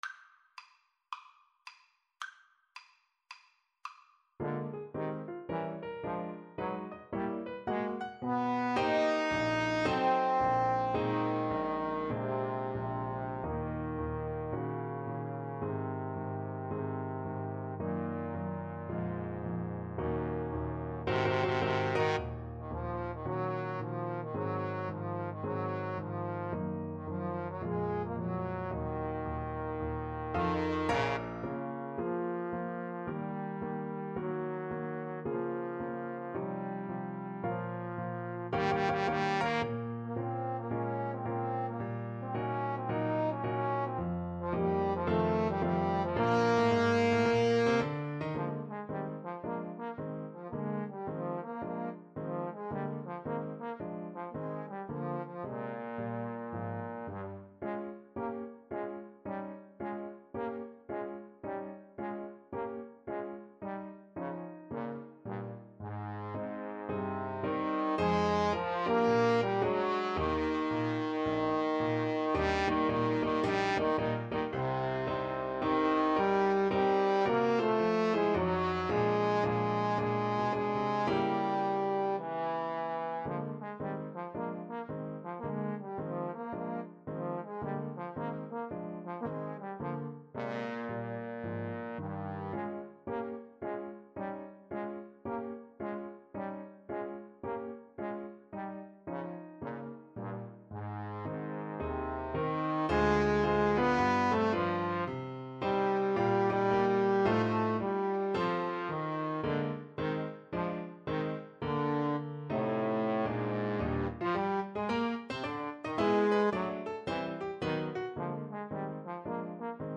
Moderato =110 swung